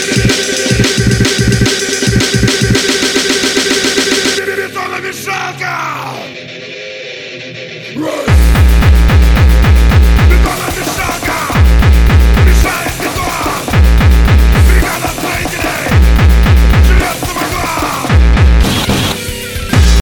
• Качество: 320, Stereo
мужской голос
жесткие
быстрые
Death Metal
Trash metal